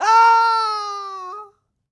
Male_Falling_Shout_01.wav